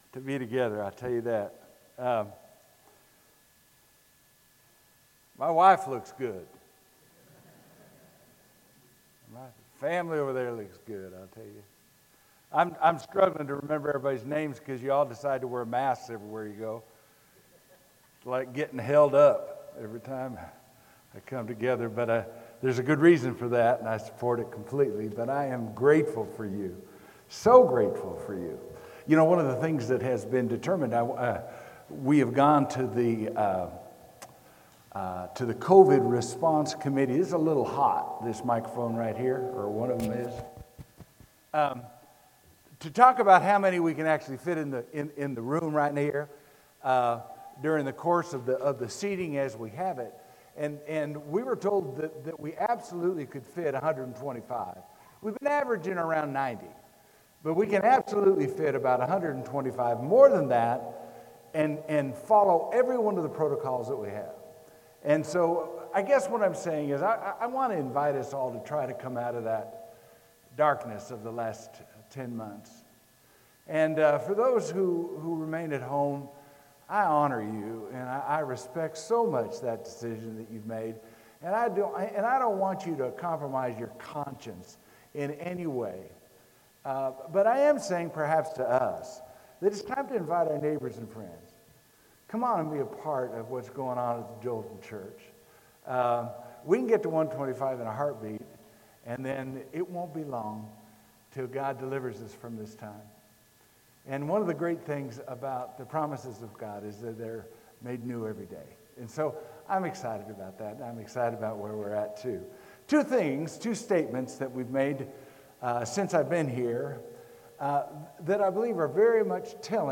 Sermon: When Love Came to Town